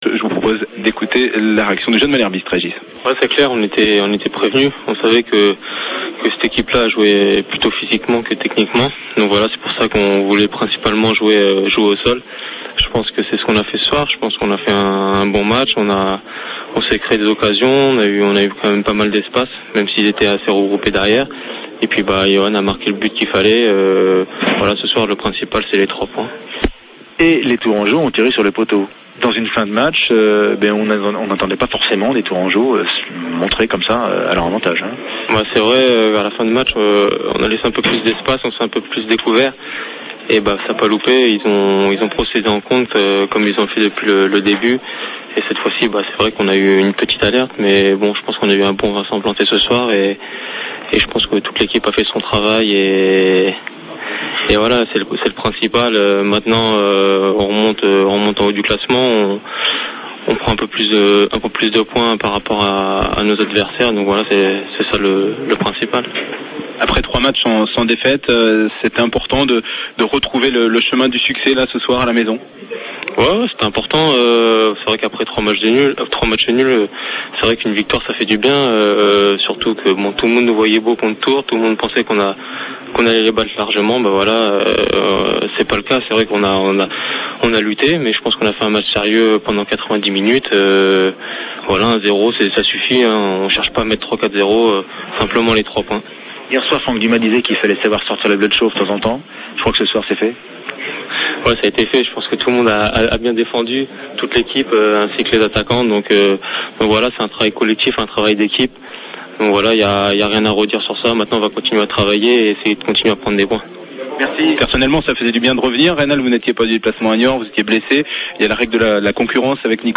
interview complète